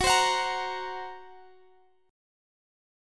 Listen to GbMb5 strummed